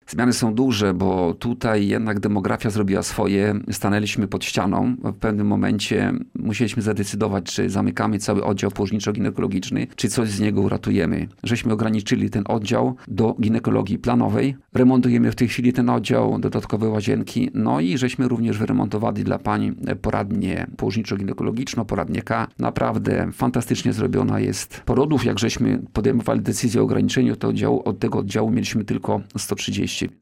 ,,Pomimo coraz mniejszej liczby urodzeń, to miejsce jest bardzo potrzebne naszym pacjentkom” – mówił na antenie Radia Nadzieja starosta kolneński, Tadeusz Klama o zmodernizowanej Poradni Położniczo-Ginekologicznej przy Szpitalu Ogólnym w Kolnie.